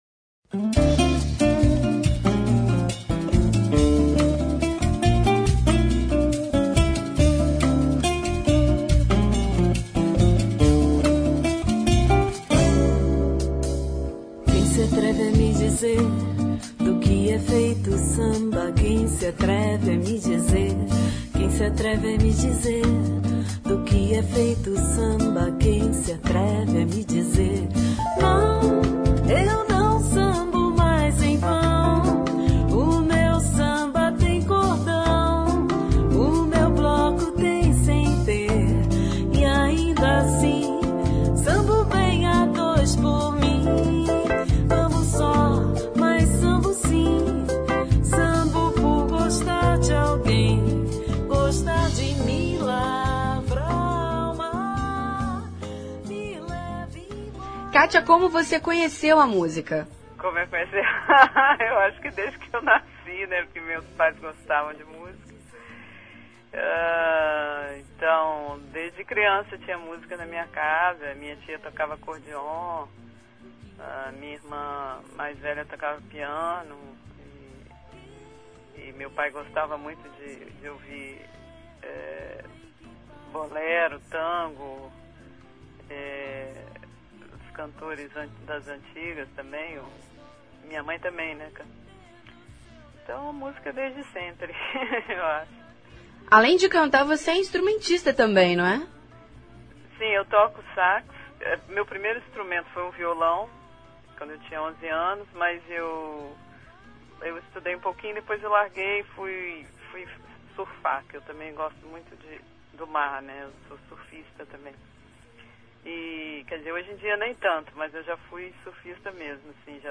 Confira a entrevista com a cantora.